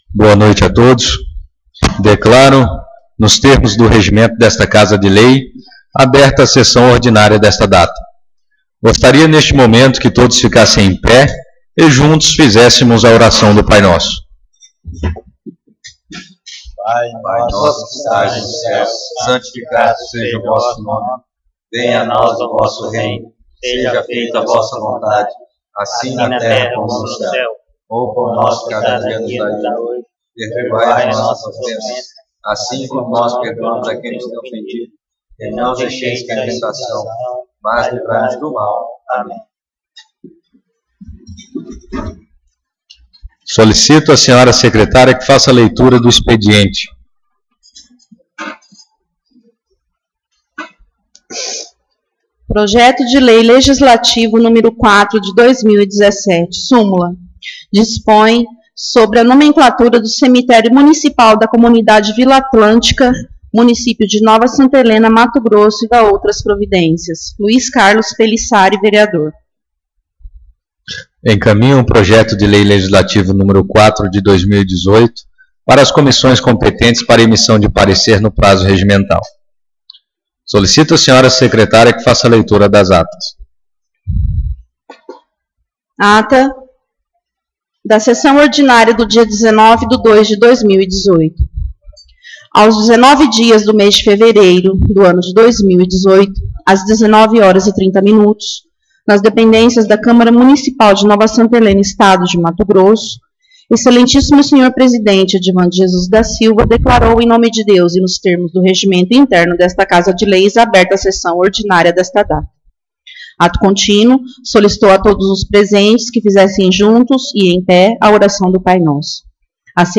Sessão Ordinária 12/03/2018